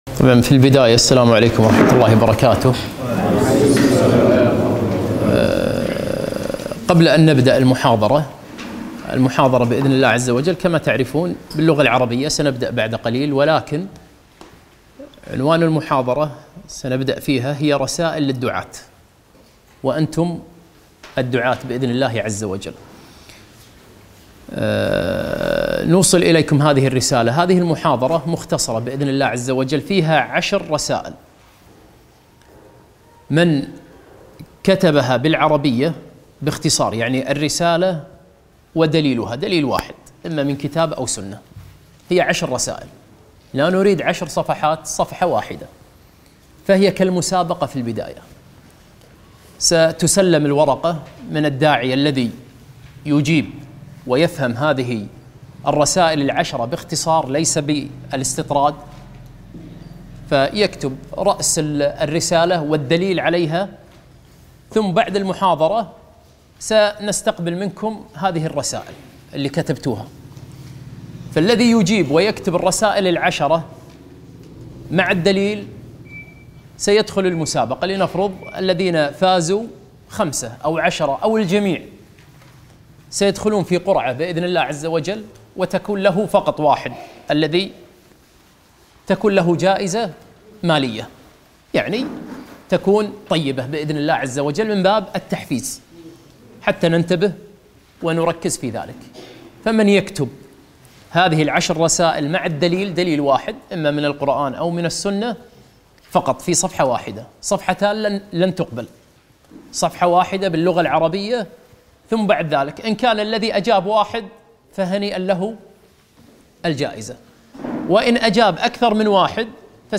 محاضرة - عشر رسائل للدعاة ضمن برنامج الدعاة